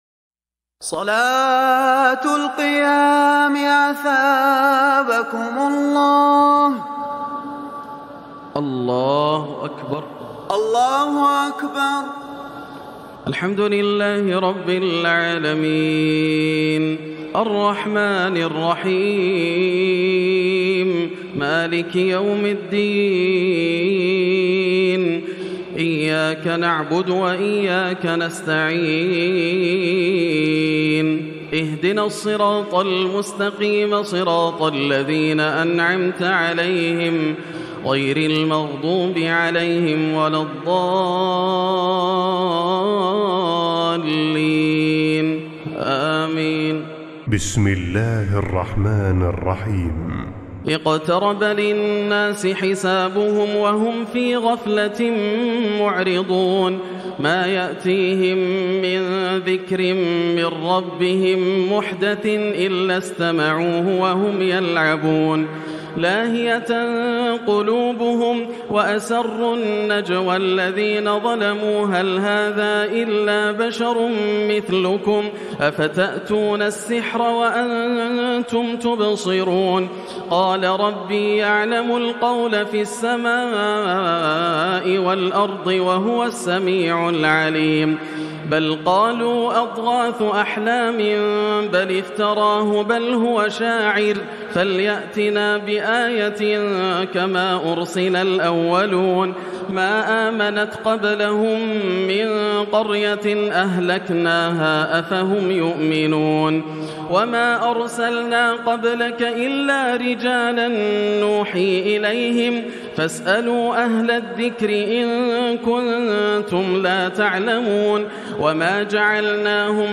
تراويح الليلة السادسة عشر رمضان 1440هـ سورة الأنبياء كاملة Taraweeh 16 st night Ramadan 1440H from Surah Al-Anbiyaa > تراويح الحرم المكي عام 1440 🕋 > التراويح - تلاوات الحرمين